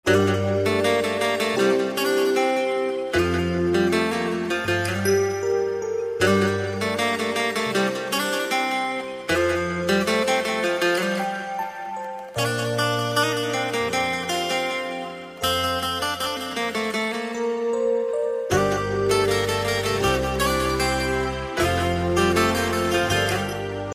Category: Guitar Ringtones